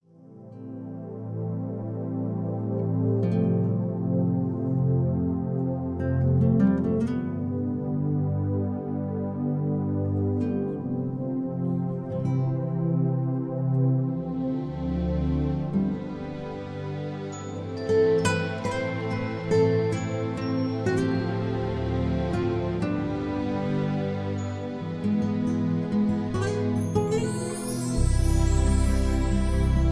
(Key-Am, Tono de Am)
mp3 backing tracks